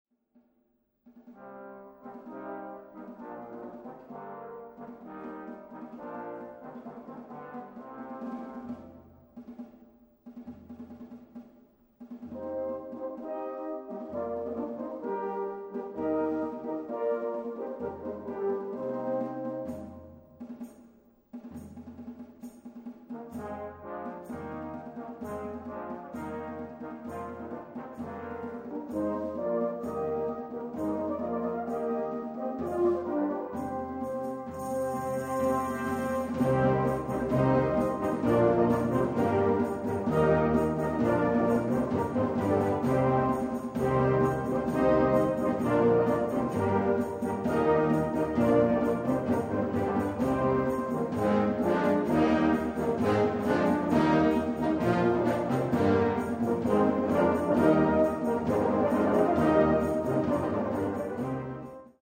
arrangement voor fanfare
met moderne ritmiek